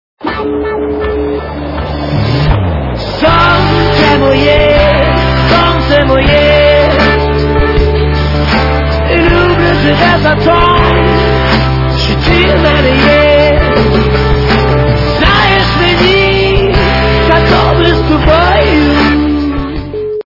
украинская эстрада